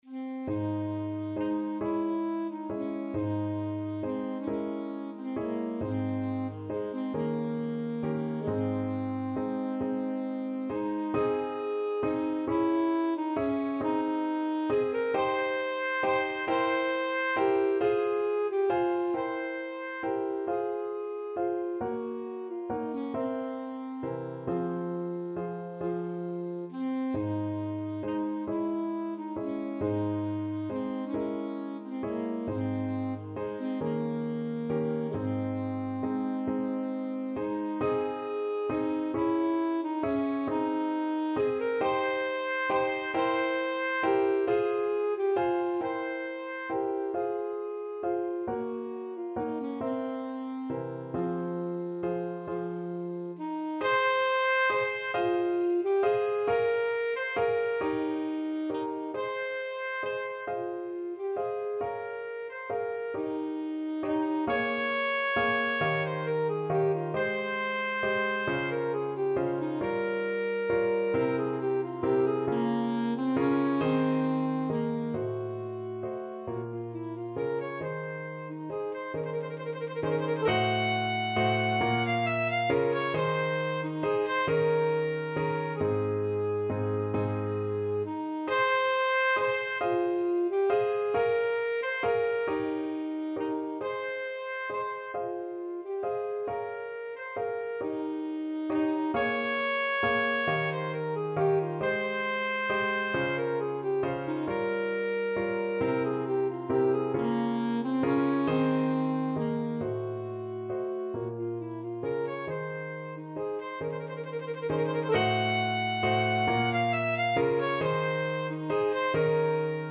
Alto Saxophone
6/8 (View more 6/8 Music)
Andantino .=c.45 (View more music marked Andantino)
Classical (View more Classical Saxophone Music)
paradis_sicilienne_ASAX.mp3